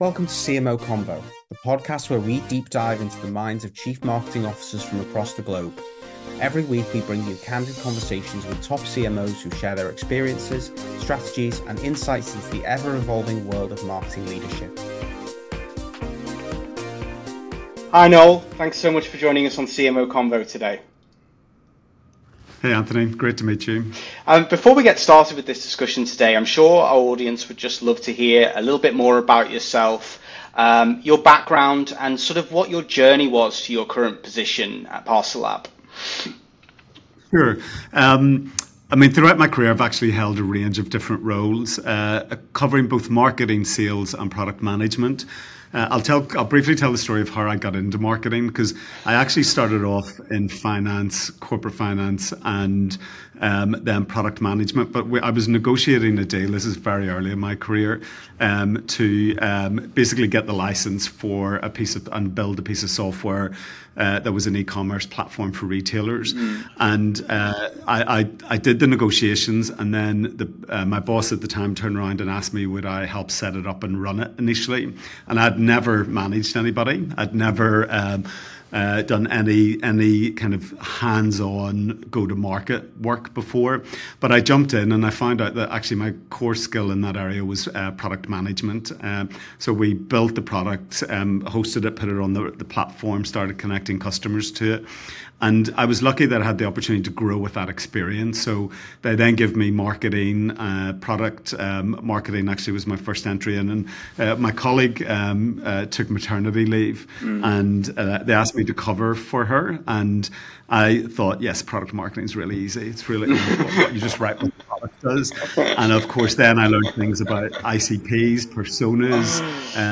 A CMO Confidential Interview